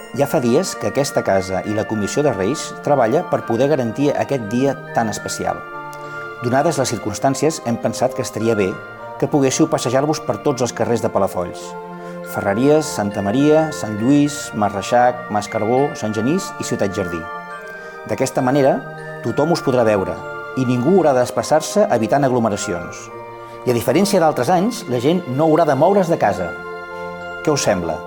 Així ho explicava l’alcalde de Palafolls als patges en el vídeo que es pot veure a internet de la visita dels patges.